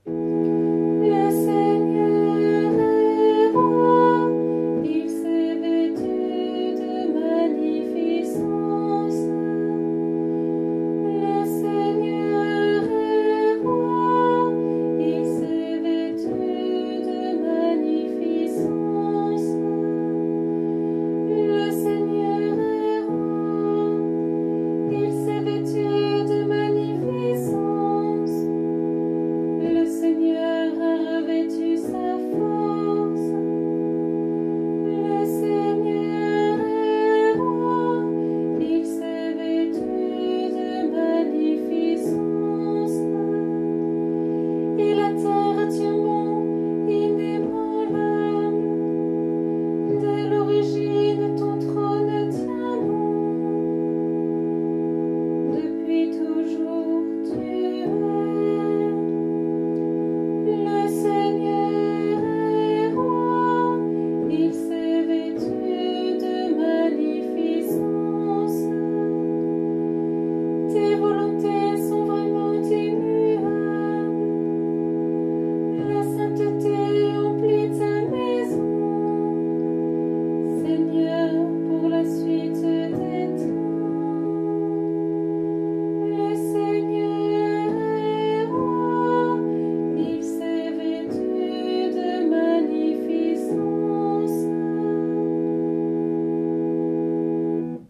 Harmonisation SATB. Enregistrement voix soprane.